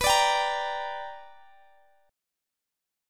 Listen to BmM7#5 strummed